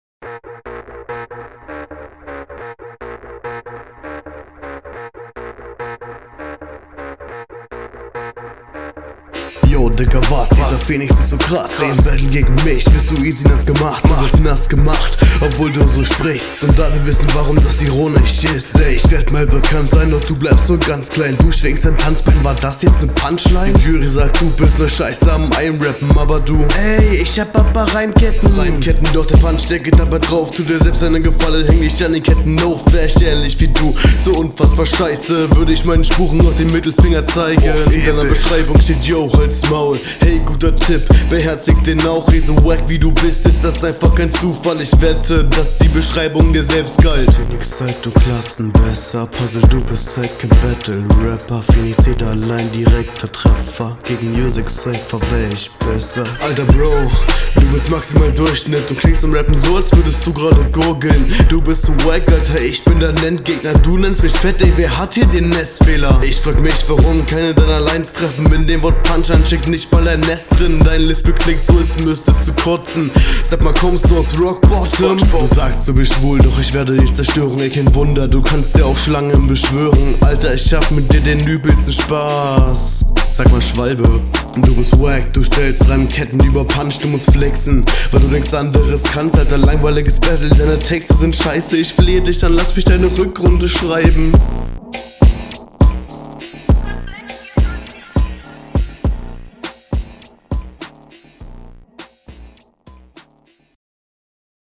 Der Beat liegt dir, bisher dein bester Flow von allen drei Runden.
Oh mehr Energie, nice.